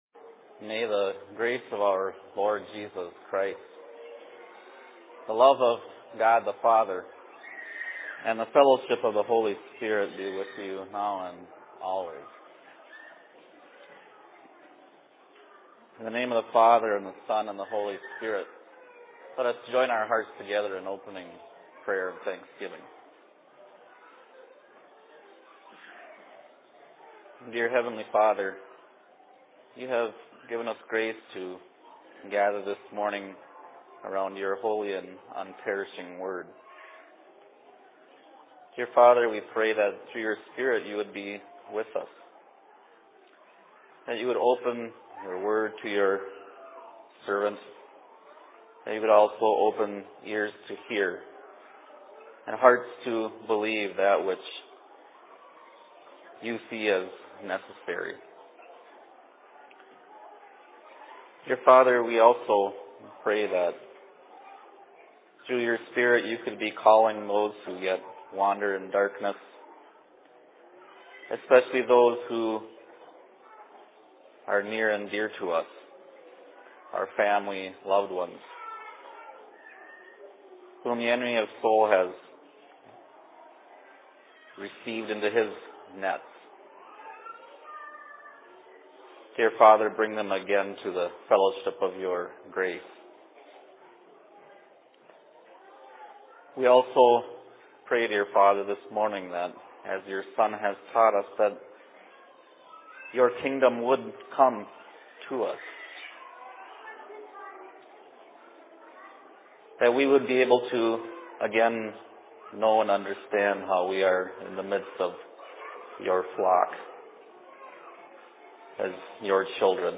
Sermon in Phoenix 28.10.2007
Location: LLC Phoenix